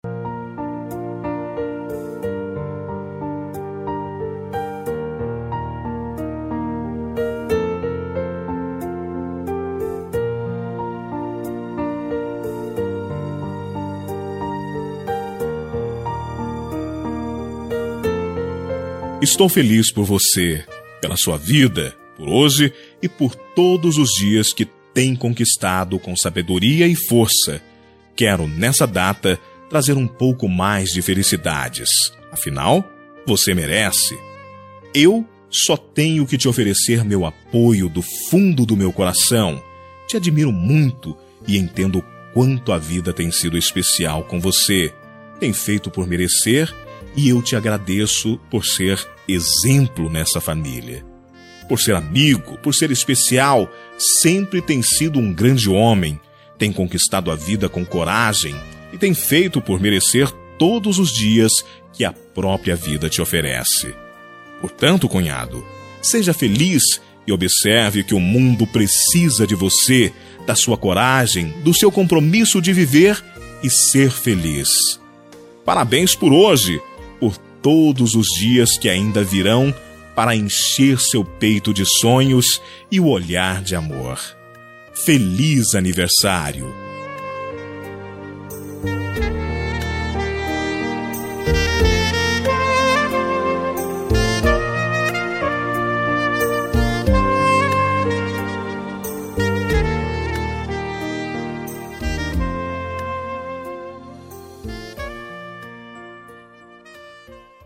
Aniversário de Cunhado – Voz Masculina – Cód: 5231